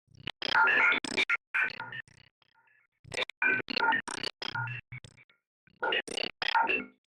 Horror
Ghost Inside the Machine is a free horror sound effect available for download in MP3 format.